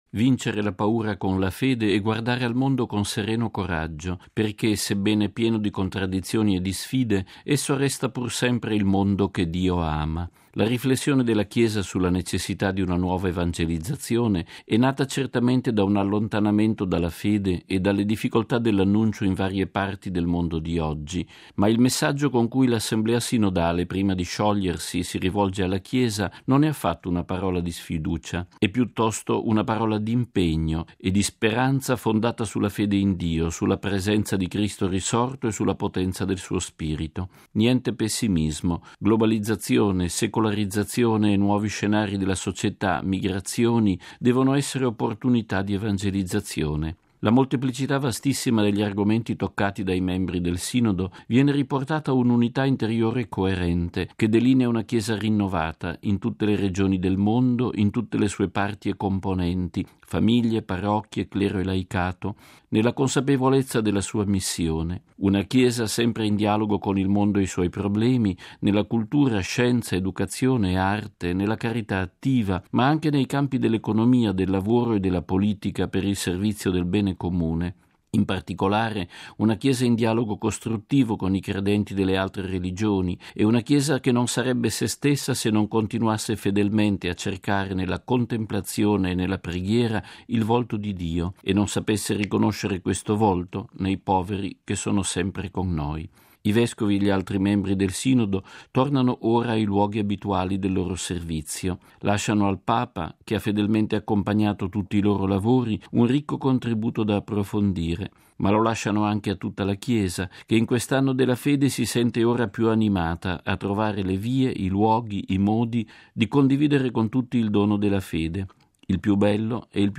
◊   Il Sinodo dei vescovi sulla nuova evangelizzazione ha voluto lanciare un messaggio di fiducia e di incoraggiamento, nonostante le tante difficoltà che sfidano oggi i credenti. Ascoltiamo in proposito il nostro direttore, padre Federico Lombardi, nel suo editoriale per Octava Dies, il Settimanale informativo del Centro Televisivo Vaticano: